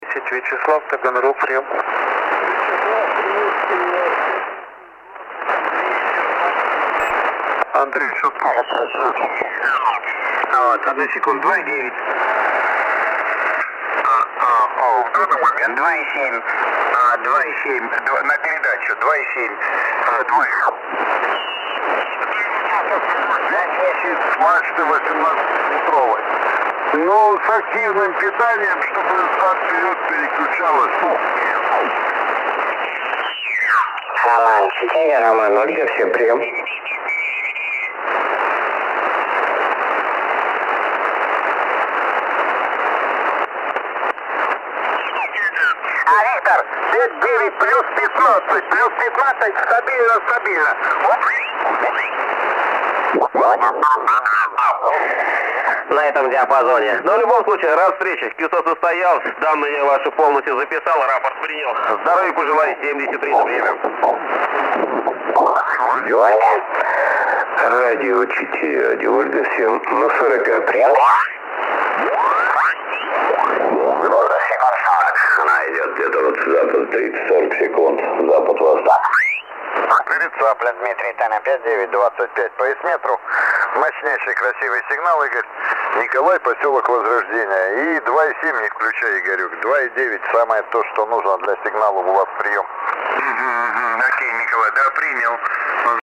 При максимальной громкости в 100% явных искажений не слышно.
Вот запись эфира на 40 м с включенным УВЧ и RF=5: